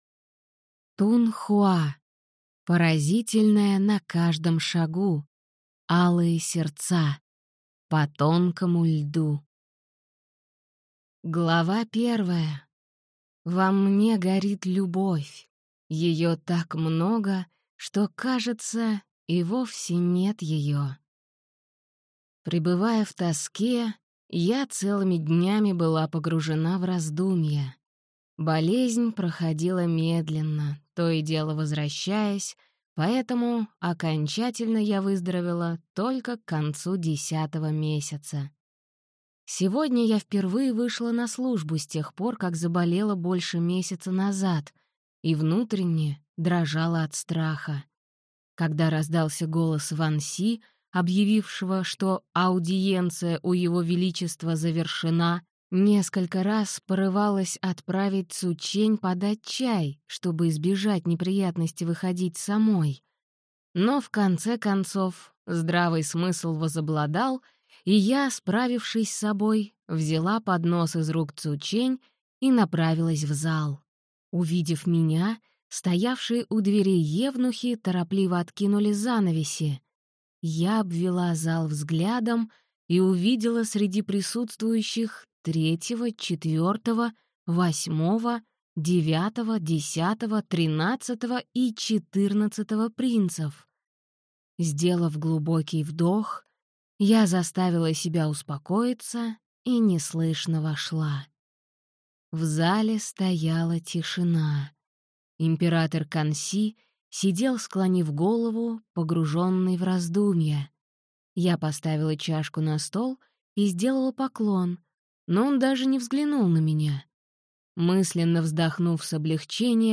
Аудиокнига Поразительное на каждом шагу. Алые сердца. По тонкому льду | Библиотека аудиокниг